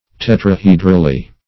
\Tet`ra*he"dral*ly\